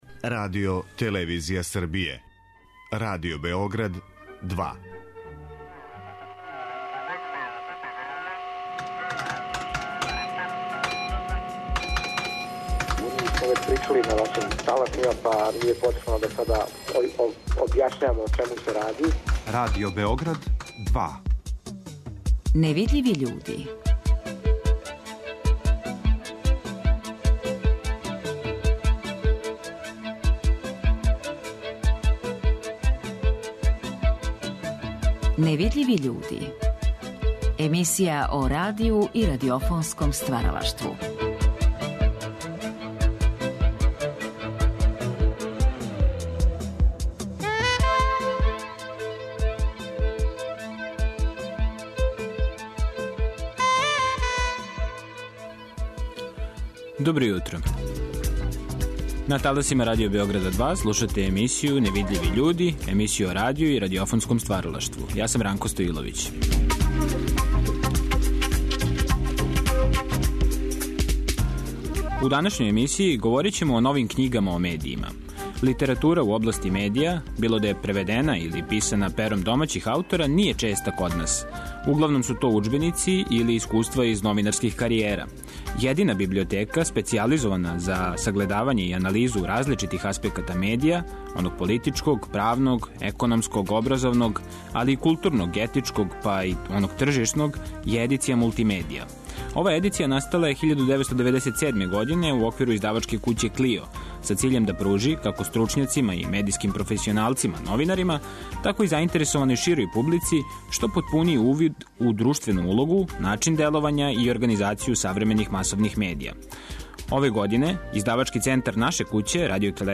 Подсетићемо се његовог деловања у Радио Београду кроз снимке из Тонског архива.
Ови разговори вођени су у оквиру циклуса "Гост Другог програма".